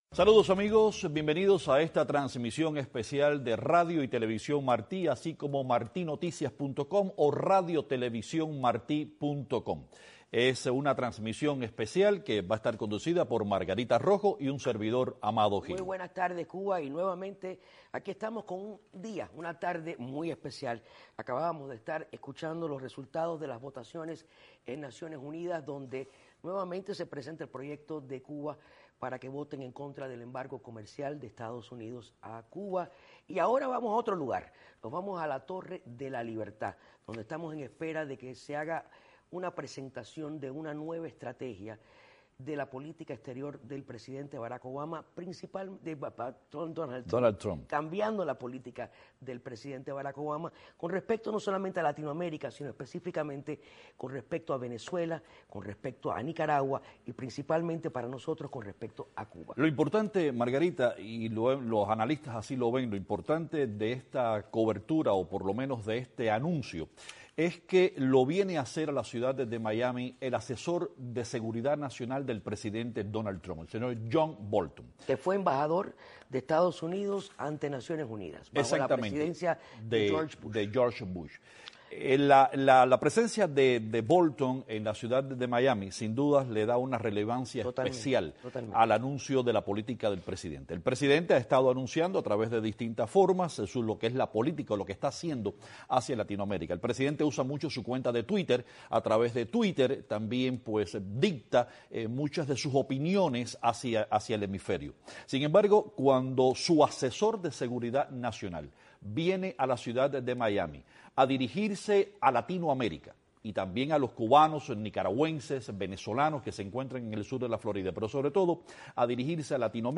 El embajador John Bolton, asesor principal de Seguridad de EEUU, en un acto en Miami aseguró que reforzarán las medidas contra las dictaduras de Cuba, Venezuela y Nicaragua. Radio Televisión Martí realizó una cobertura especial desde la emblemática Torre de la Libertad.